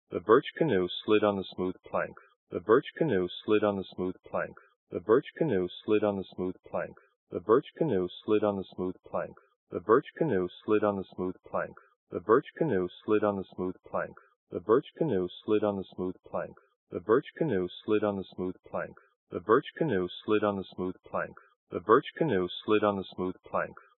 The angle in the horizontal plane (azimuth) varies from 0 to 180 degrees, in steps of 20 degrees. The angle in the vertical plane (elevation) is zero.
The HRTF filters are applied to the left channel only.
After compression and decompression
by the VLC 3D 48 codec at 24000 bps
reference_male_2_vlc_v7_3d_48.wav